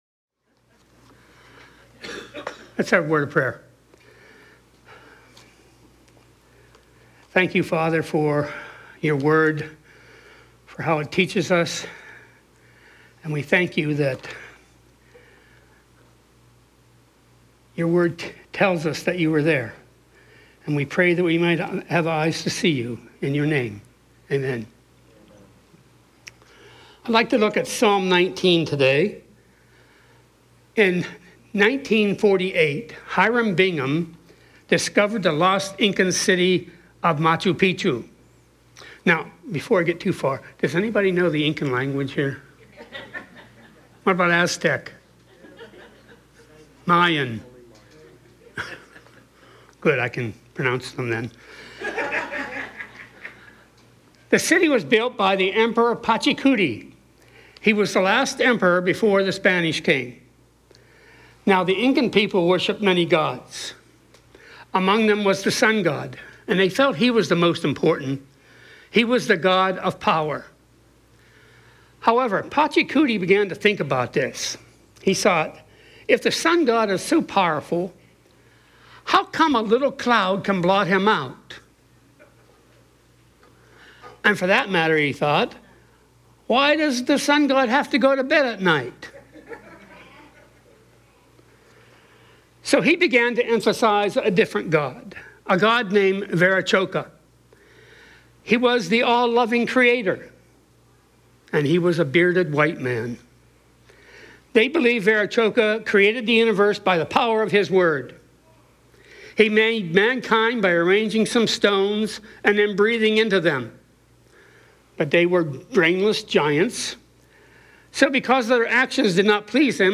Guest Speakers The God Who Speaks